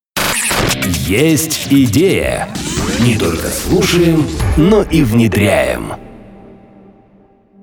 джинглы